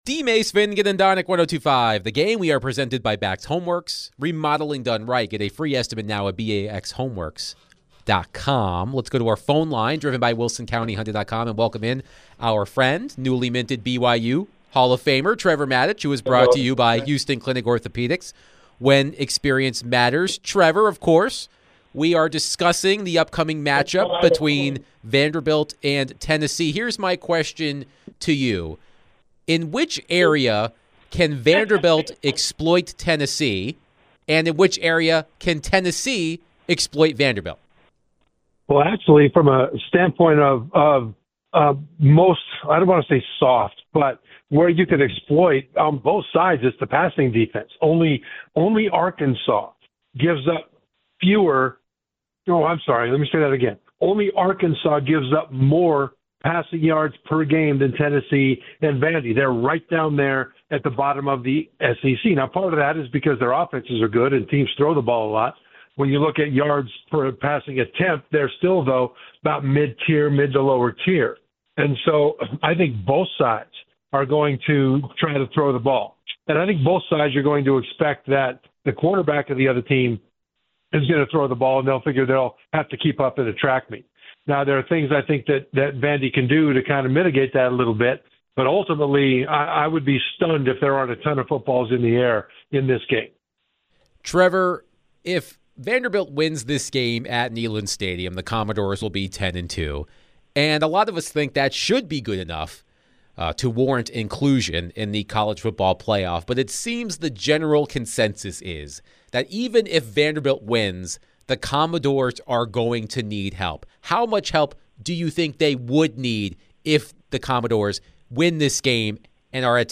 ESPN NFL/CFB Analyst Trevor Matich joins DVD to discuss the Titans,. Vols, Vandy, CFP Rankings tonight and more